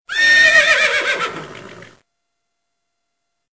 • Качество: 128, Stereo
конь
лошадь